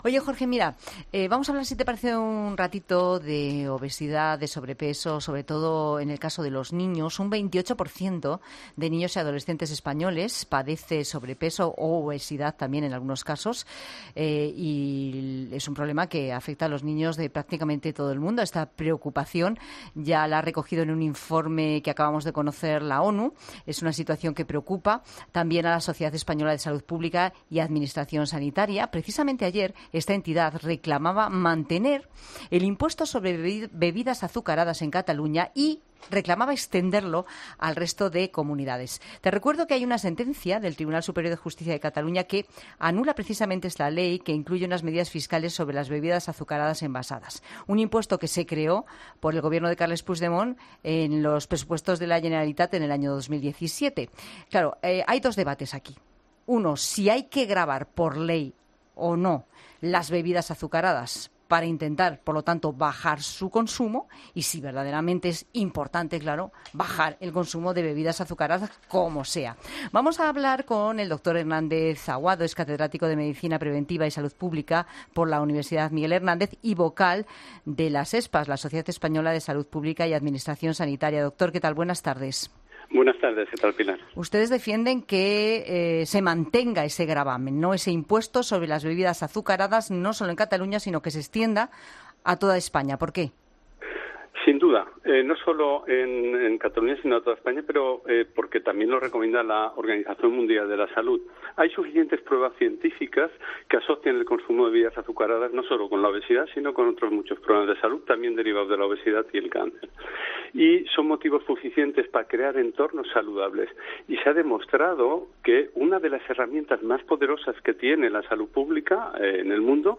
En la Tarde en COPE, ha estado el catedrático de Medicina